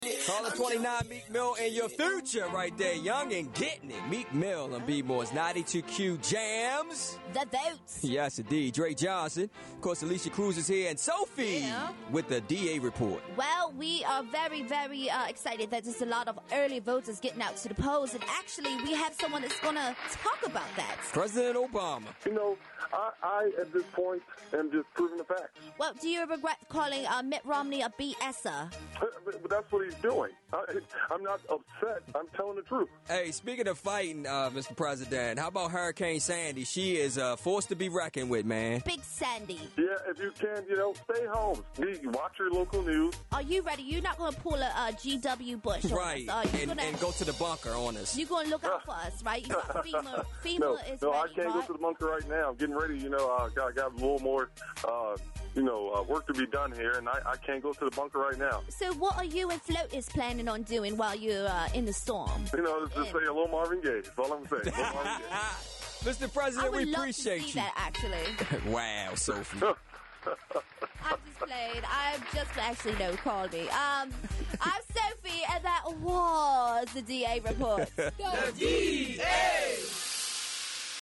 We caught up with President Obama and got him to talk about this crazy election and Hurricane Sandy.